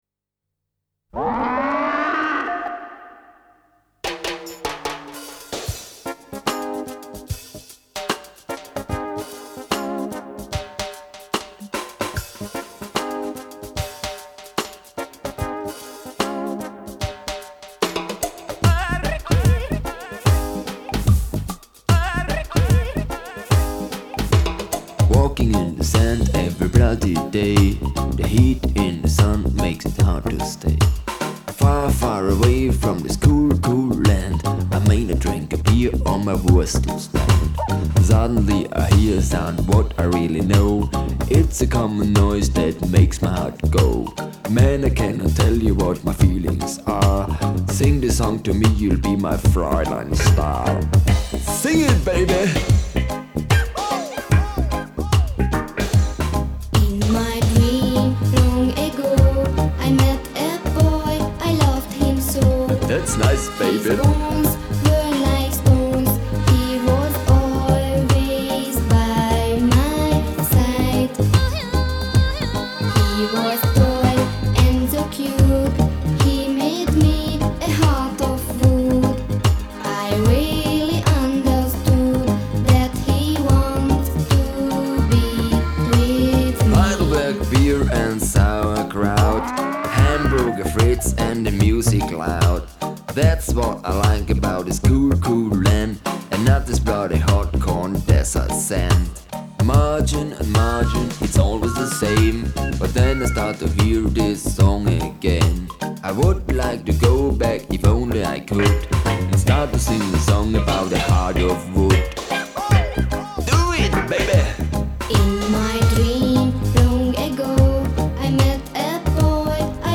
Mit dem Song „Heart of Wood“ möchte ich bei euch ein wenig Sommerfeeling und gute Laune verbreiten.
Freut euch des Lebens, der sommerlichen Temperaturen und dem coolen Rhythmus dieses Liedes.